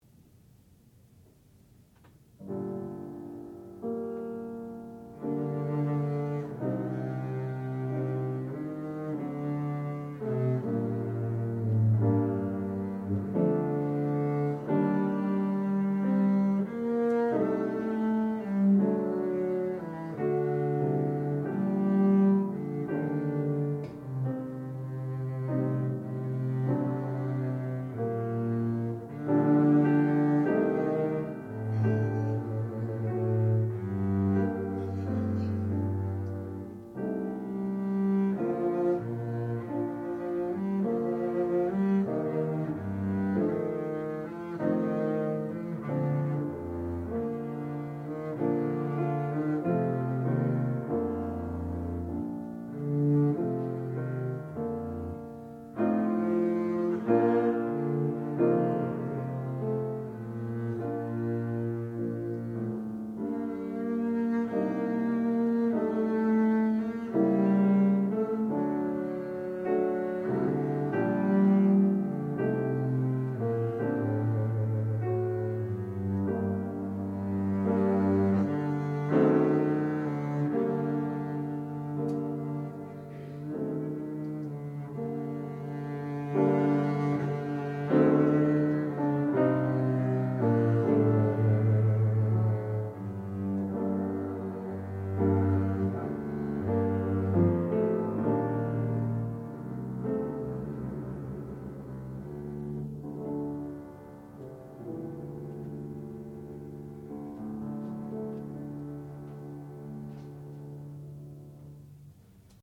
sound recording-musical
classical music
piano
double bass
Qualifying Recital